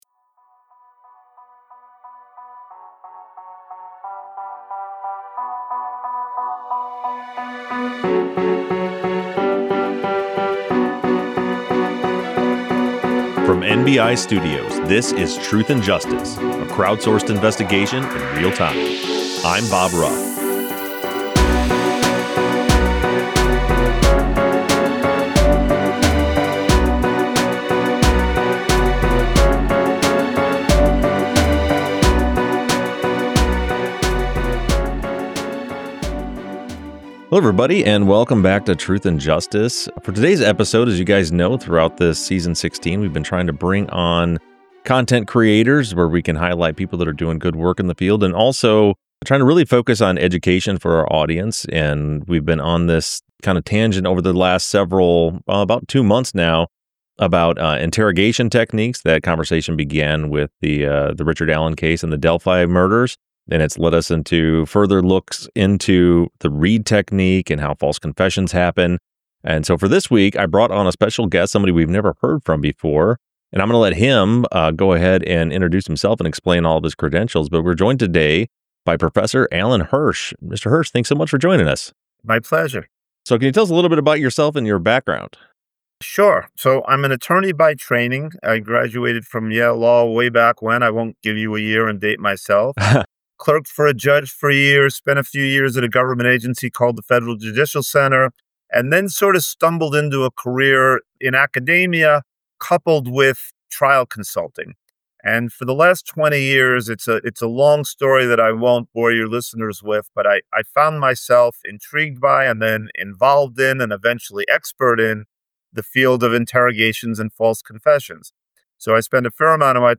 false confession expert